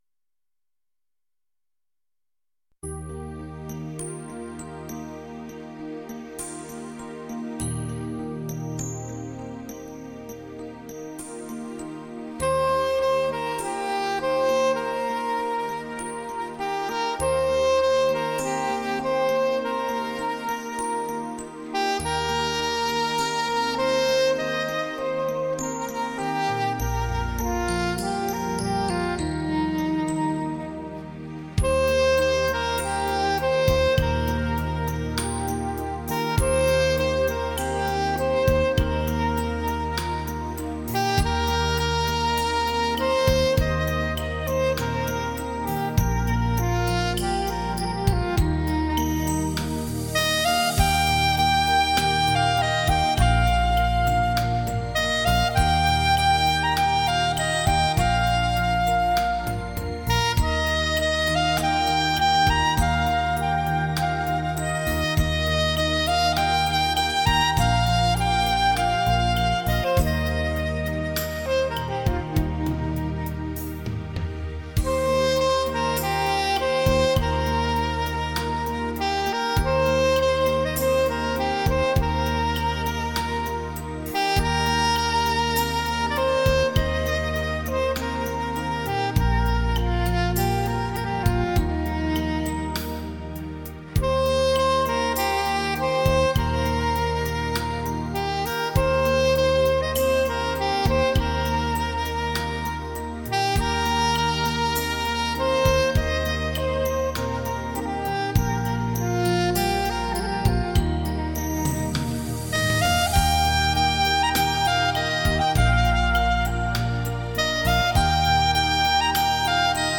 情感萨克斯
萨克斯演奏